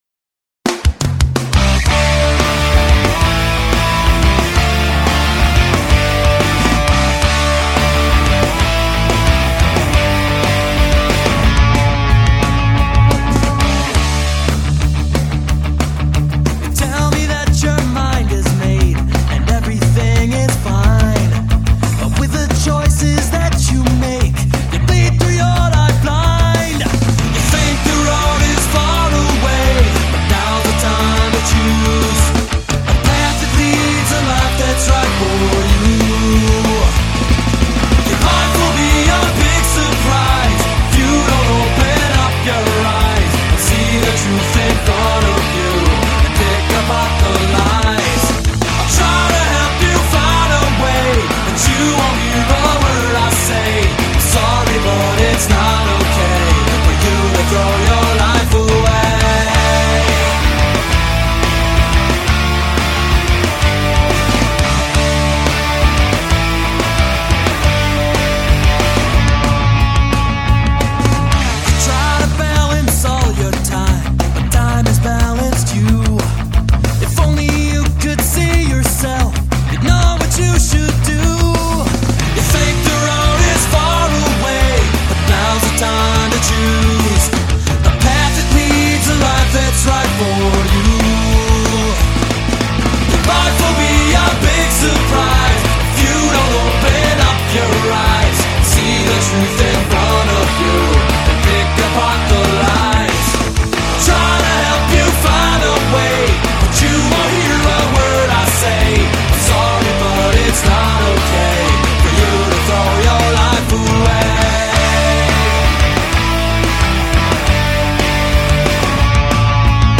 A darker tune, closer to Pennywise‘s more melodic tracks.